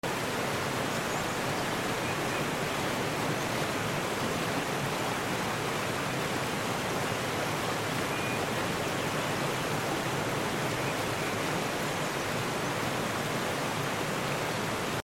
Standing In A Peaceful Alcove, Sound Effects Free Download